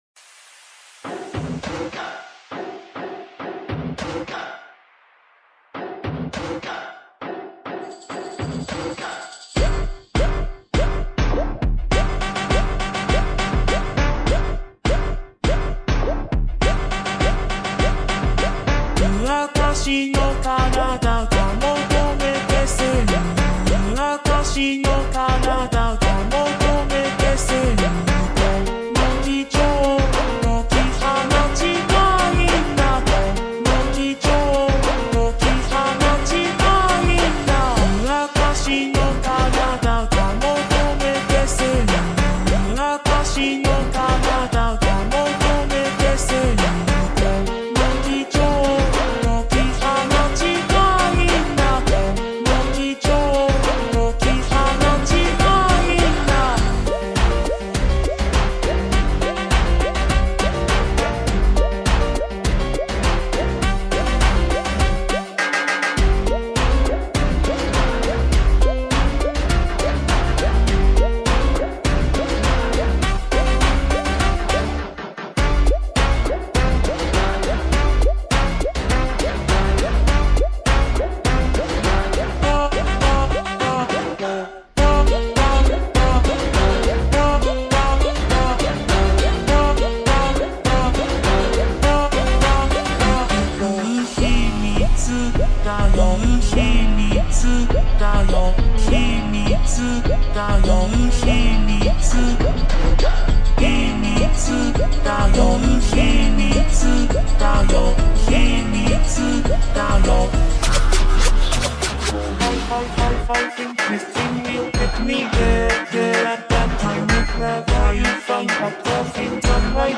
در ورژن کند شده و Slowed مناسب ادیت
فانک